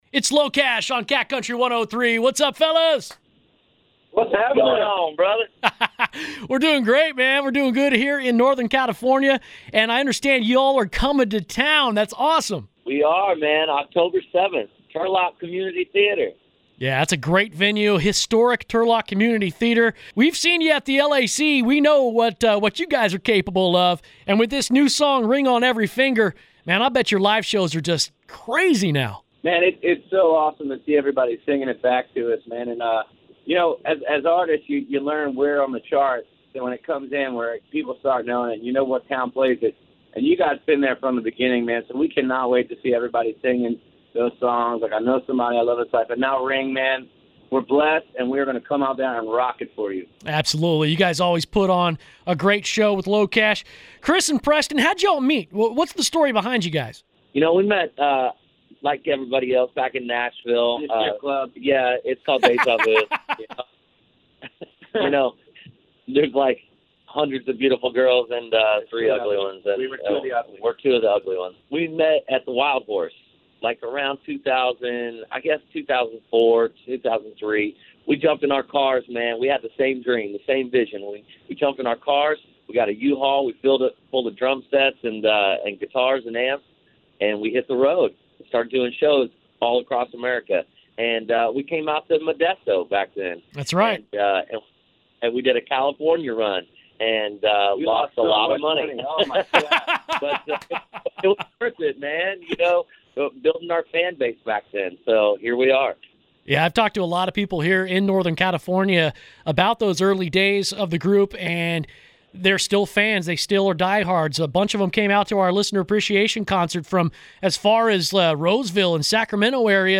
locash-interview.mp3